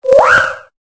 Cri de Chinchidou dans Pokémon Épée et Bouclier.